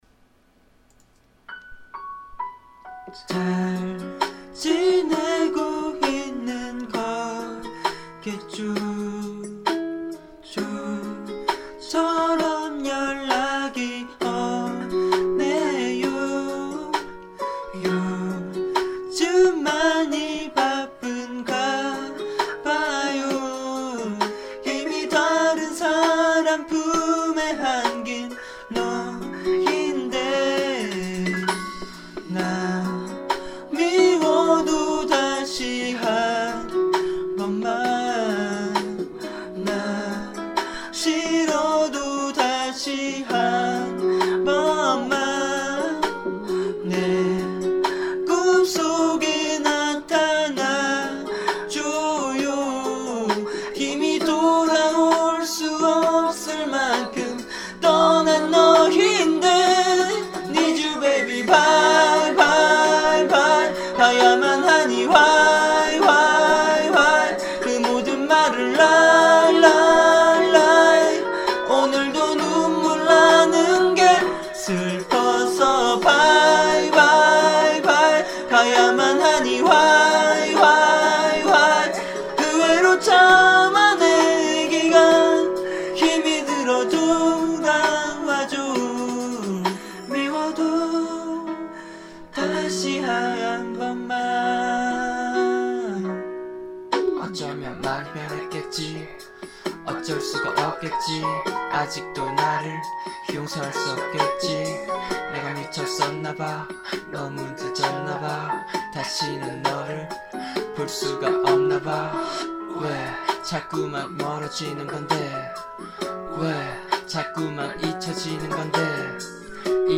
직접 부른 노래를 올리는 곳입니다.